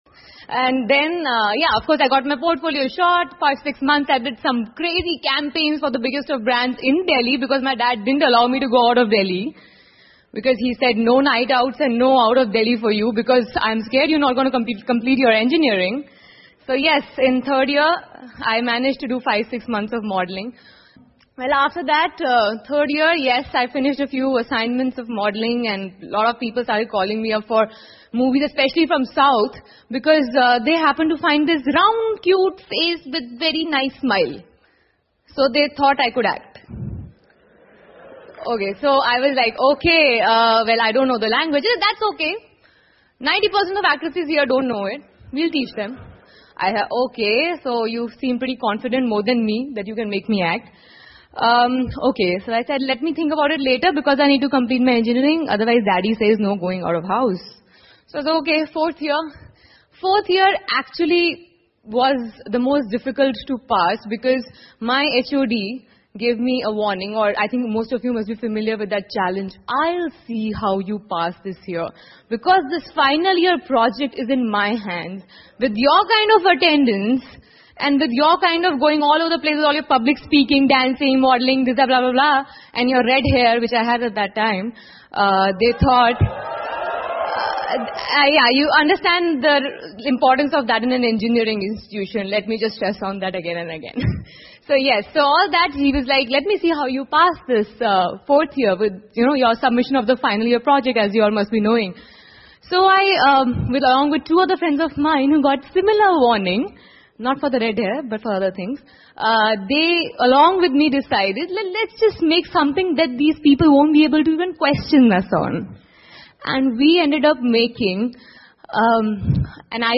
英文演讲录 塔丝·潘努：从程序员到演员(2) 听力文件下载—在线英语听力室